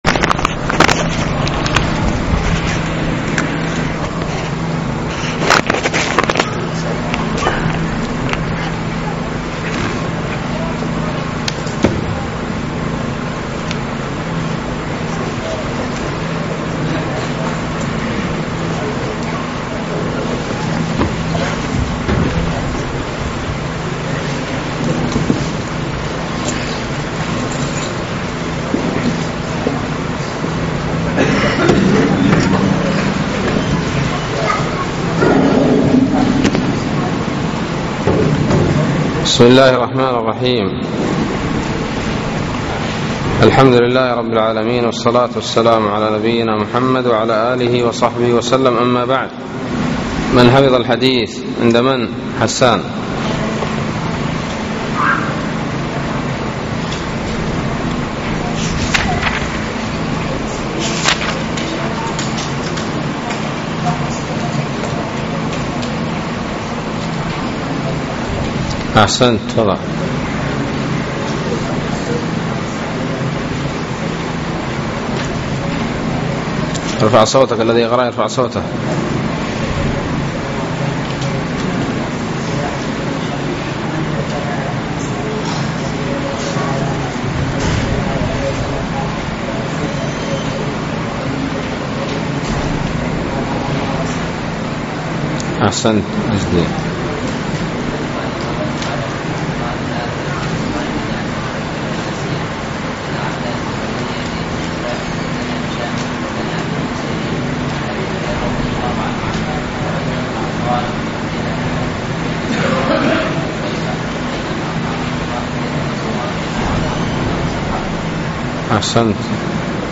بسم الله الرحمن الرحيم الدرس الواحد والعشرون : بَاب: قَوْلِ النَّبِيِّ : إِذَا تَوَضَّأَ فَلْيَسْتَنْشِقْ بِمَنْخِرِهِ الْمَاءَ، وَلَمْ يُمَيِّزْ بَيْنَ الصَّائِمِ وَغَيْرِهِ وبَاب: إِذَا جَامَعَ فِي رَمَضَانَ